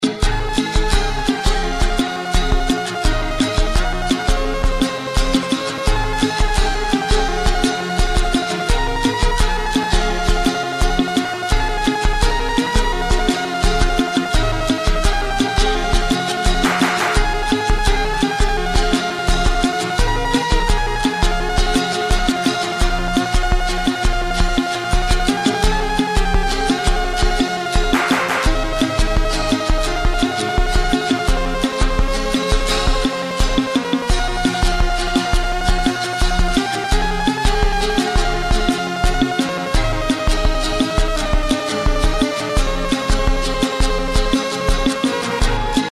Instrument 1
Also mal abgsehen davon, dass das Melodieinstrument in beiden fällen extrem unsauber klingt (vielleicht ist das auch gewollt?), würde ich auf Synthesizer/Keyboard tippen.
Na wenn, dann ist das ein verfremdetes oder mit zittrigen Händen gespieltes Theremin.
Klingt beides Soundsamples wie sie oft in Chiptunes verwendet wurden (stichwort tracker (+ evtl eben noch n filter drüber)), 2. evtl ne Saw? kenn mich nicht so gut aus.